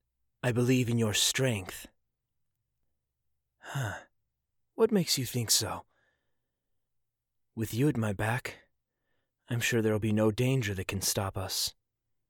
Voice: Calm and collected, smooth, soft sounding, and somewhat elegant.